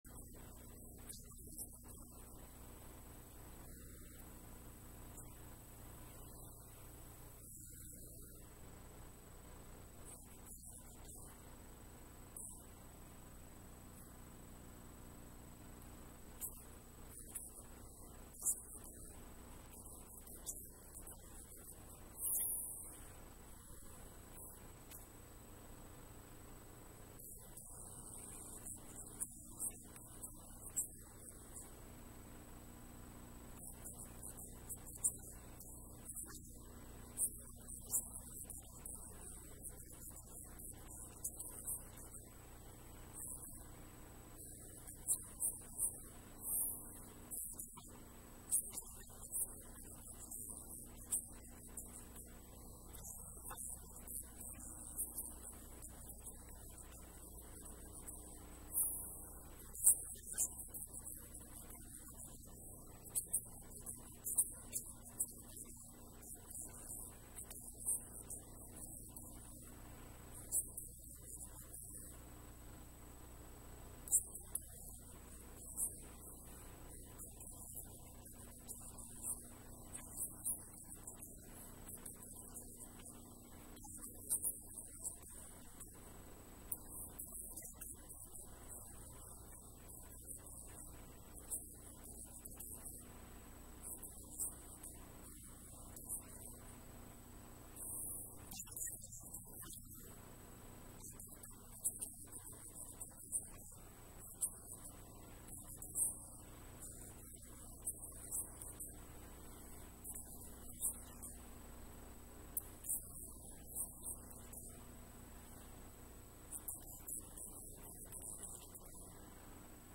2021년 5월 2일 주일 4부 예배